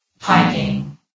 New & Fixed AI VOX Sound Files
piping.ogg